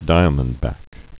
(dīə-mənd-băk, dīmənd-)